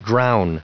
Prononciation du mot drown en anglais (fichier audio)
Prononciation du mot : drown